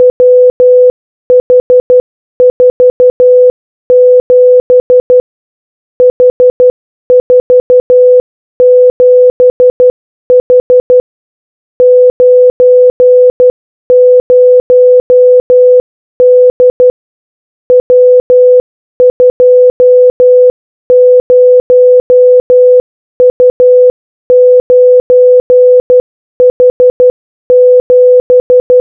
morse_chal.wav